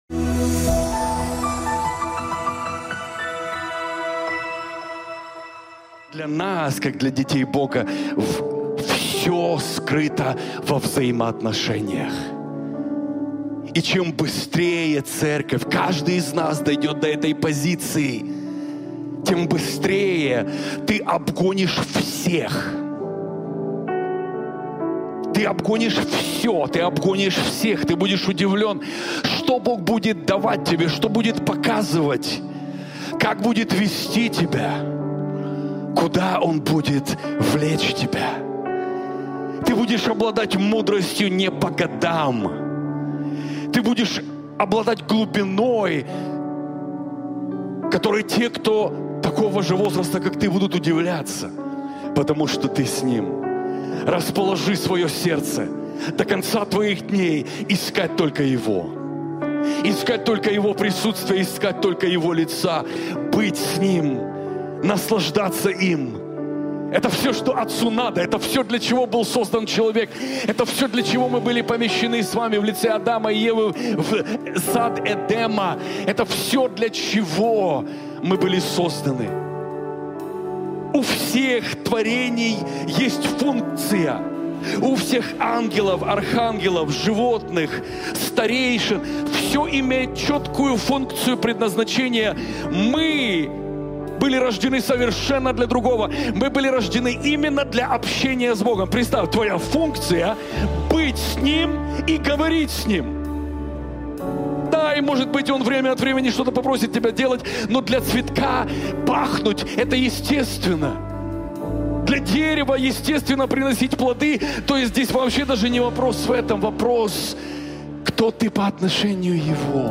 МОЛИТВЫ ЦЕНТРА ТРАНСФОРМАЦИИ
Transformation Center Молитва 848 МОЛИТВА_848.mp3 Категория : Молитвы Центра